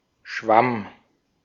Ääntäminen
IPA : /ˈfʌŋ.ɡəs/